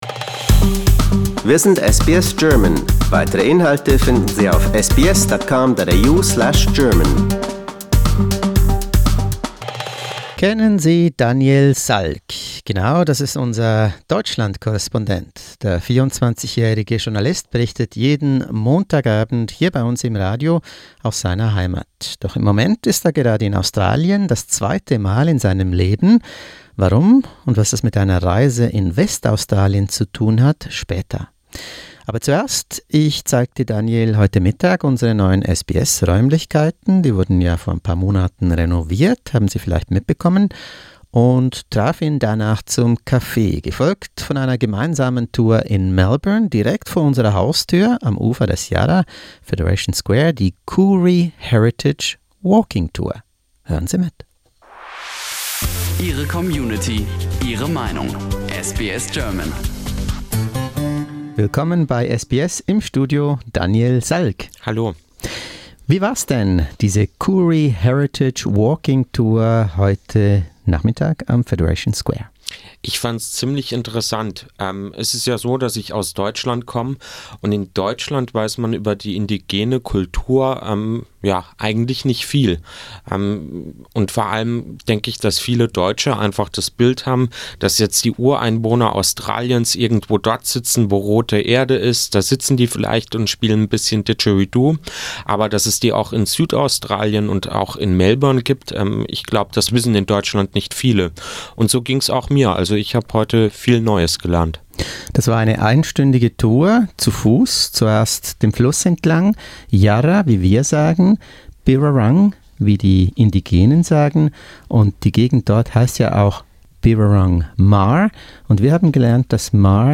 SBS German went on a guided tour at Melbourne's Federation Square. We learnt how the indigenous population survived and thrived for thousands of years before white colonialisation, living in harmony with nature.